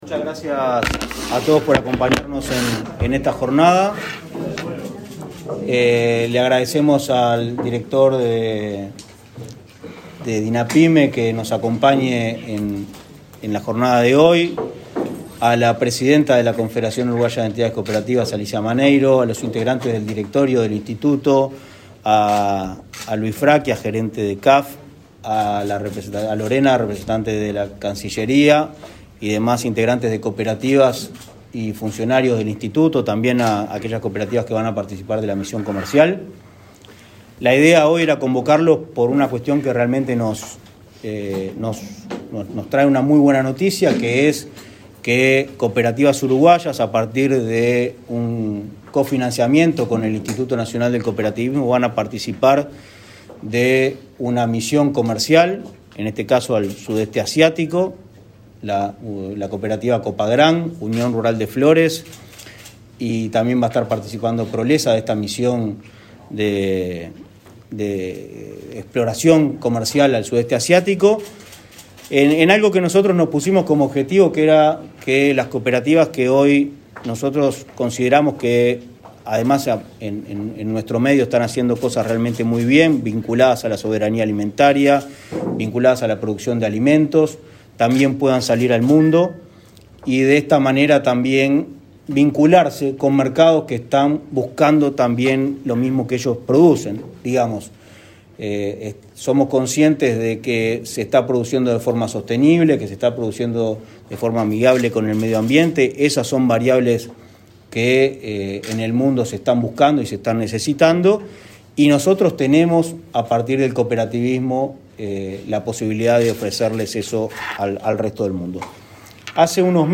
Palabras de autoridades en acto de Inacoop
El Instituto Nacional del Cooperativismo (Inacoop) presentó una misión comercial que viajará al sudeste asiático, en el marco de la política implementada para abrir las cooperativas al mundo. El presidente del organismo, Martín Fernández, y el director nacional de Artesanías, Pequeñas y Medianas Empresas, del Ministerio de Industria, Energía y Minería, Gonzalo Maciel, destacaron la importancia del ingreso a nuevos mercados.